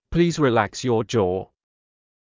ﾌﾟﾘｰｽﾞ ﾘﾗｯｸｽ ﾕｱ ｼﾞｬｱ